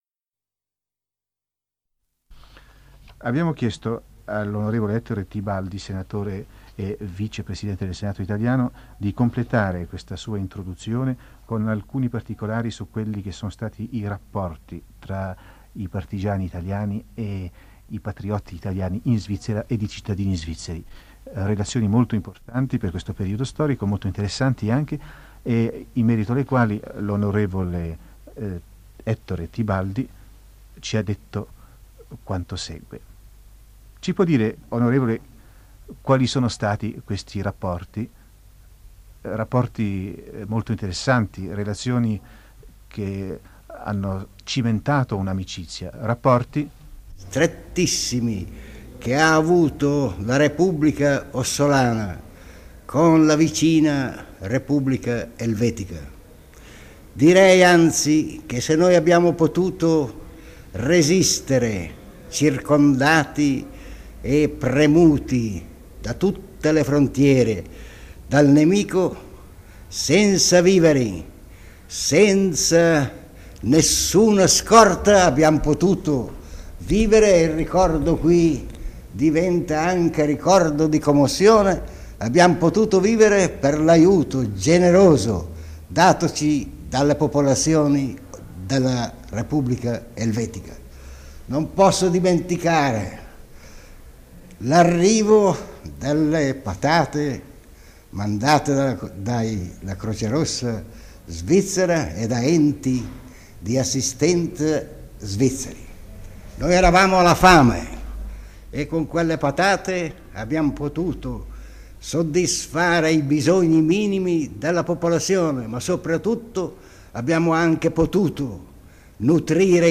Intervista a Ettore Tibaldi